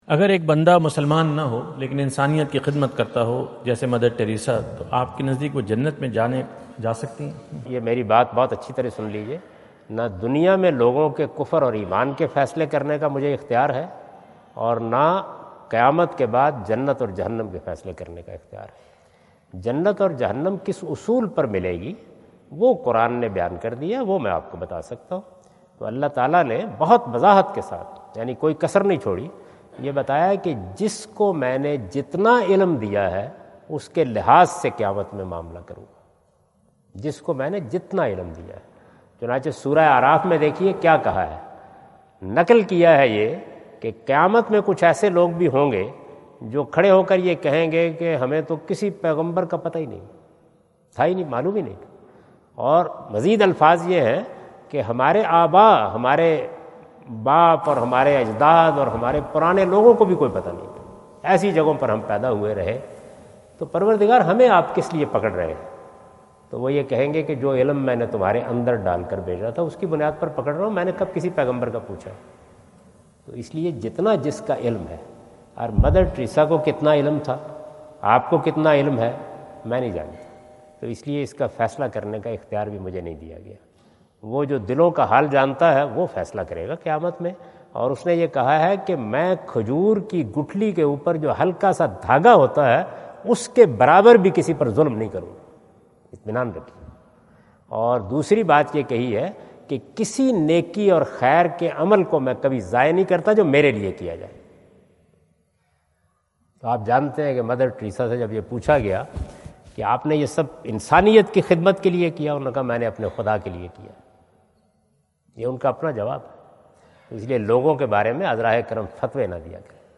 Javed Ahmad Ghamidi answer the question about "Can non-Muslims go to Jannah?" During his US visit at Wentz Concert Hall, Chicago on September 23,2017.